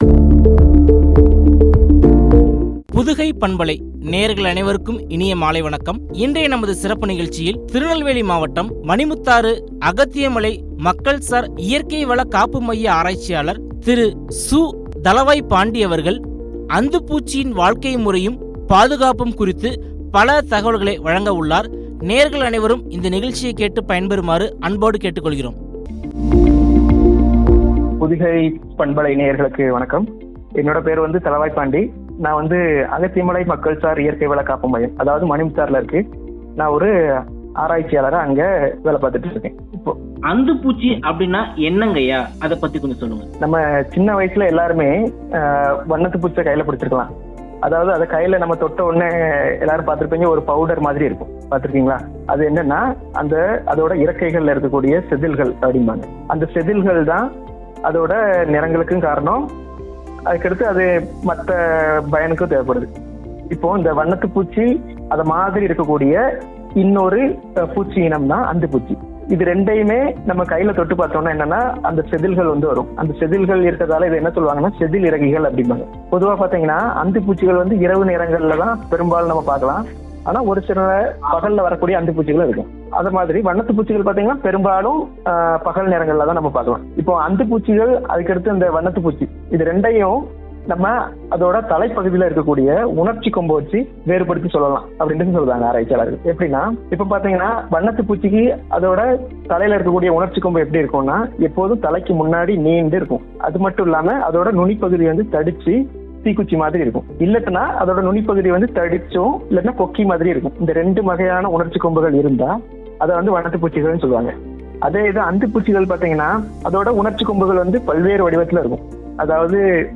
பாதுகாப்பும்” குறித்து வழங்கிய உரையாடல்.